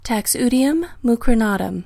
Pronounciation:
Tax-O-dee-um moo-crow-NAA-tum